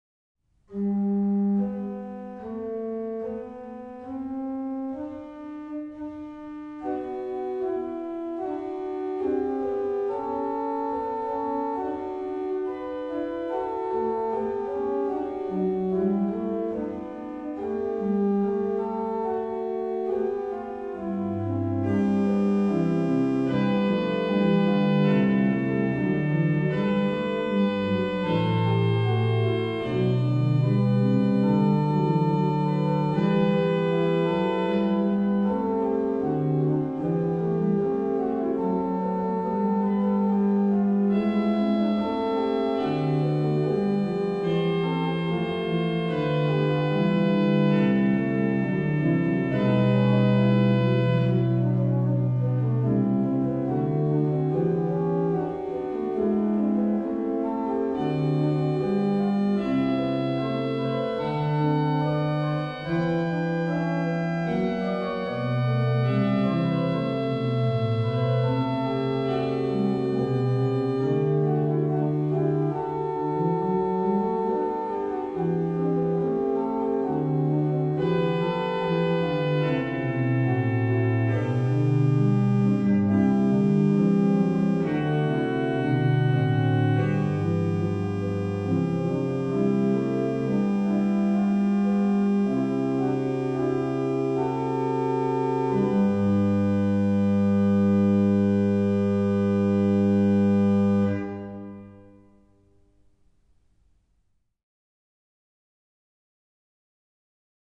Registration   RP: Pr8
Ped: Corn2